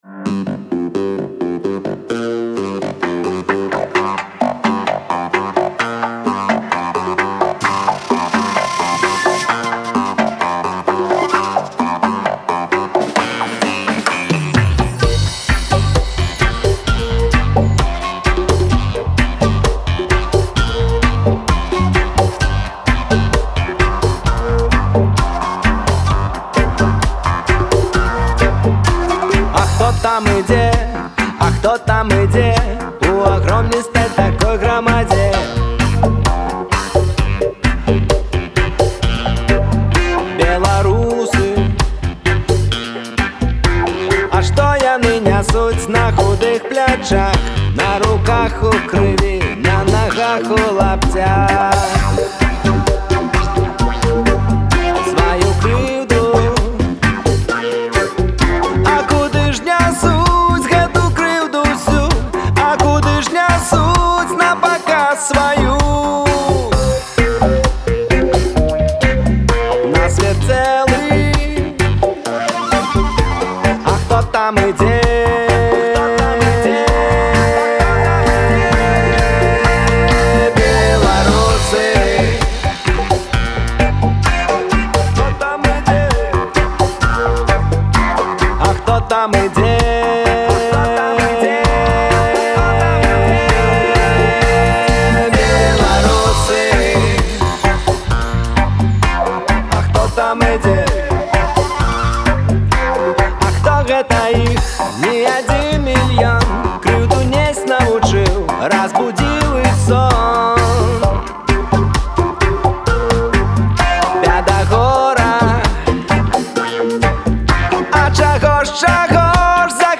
рэгі-каманда